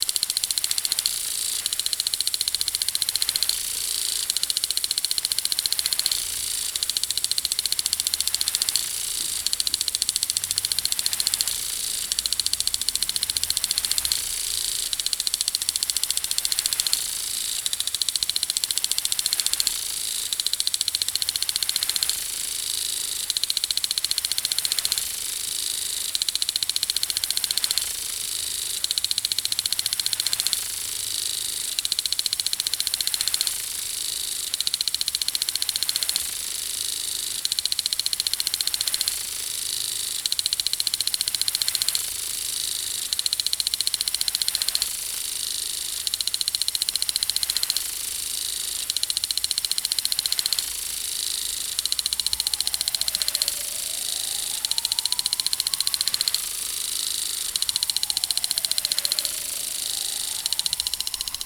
• plastic sprinkler.wav
Alternative for hi-hats, sprinkler recorded in the block garden, watering the lawn.
plastic_sprinkler_sgf.wav